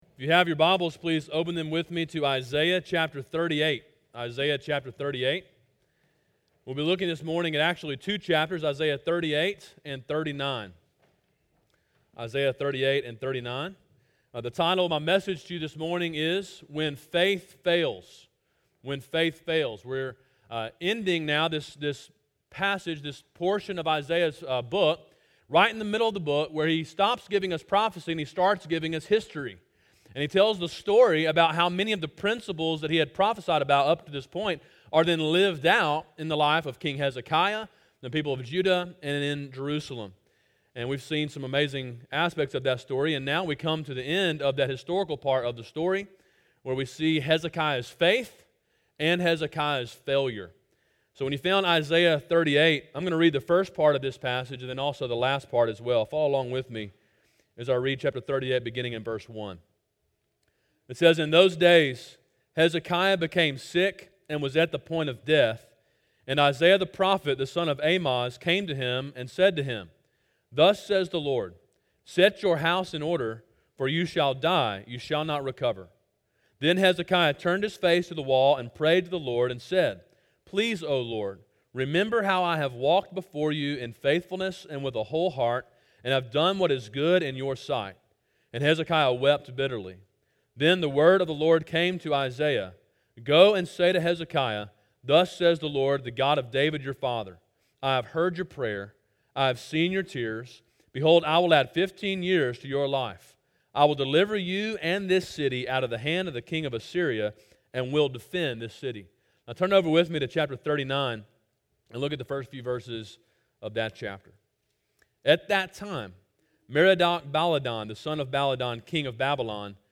Sermon: “When Faith Fails” (Isaiah 38-39) – Calvary Baptist Church